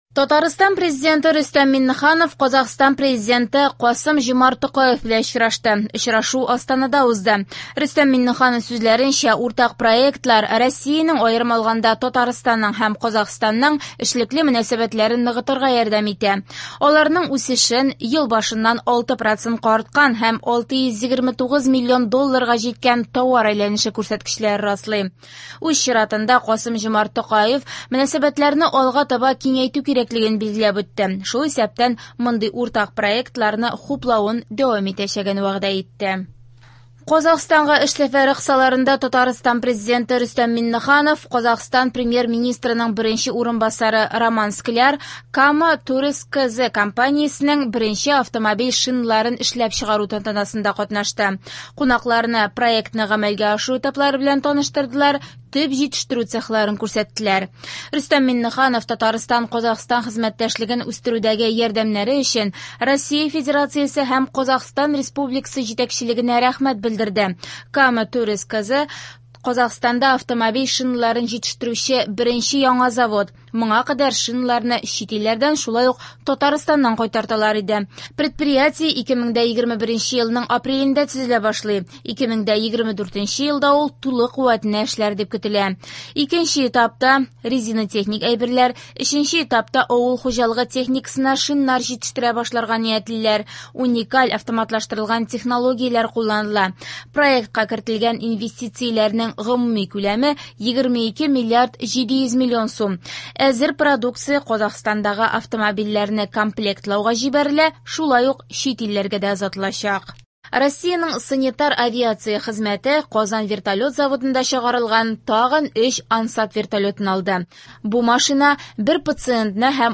Яңалыклар (13.12.22)